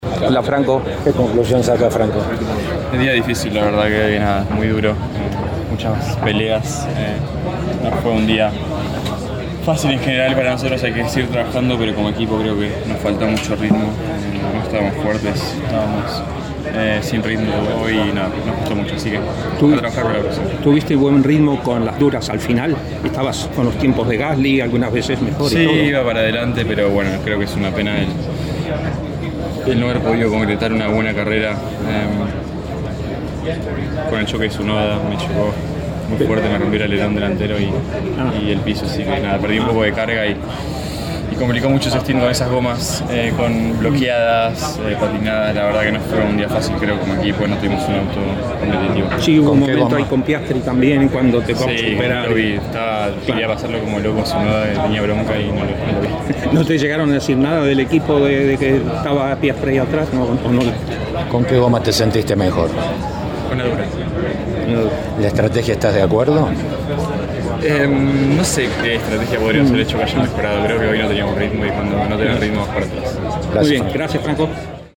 El piloto argentino habló en una rueda de prensa de la que formó parte Campeones luego del Gran Premio de Austria, donde comentó lo que dejó su puesto 15 en Spielberg.